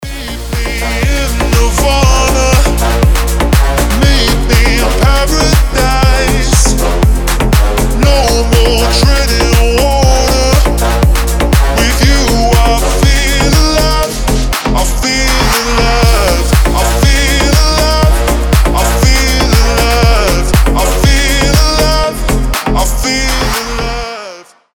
• Качество: 320, Stereo
мужской голос
громкие
slap house